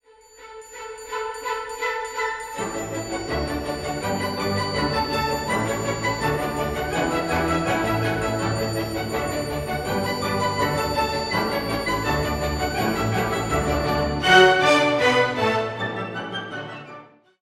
Pour nous accompagner dans cette nouvelle année, un peu de gaieté et de bonne humeur avec la mélodie très célèbre de Jacques OFFENBACH.
Ce compositeur français écrit en 1868 le galop infernal d'Orphée aux Enfers, arrangé par la suite en French Cancan. Air connu dans le monde entier !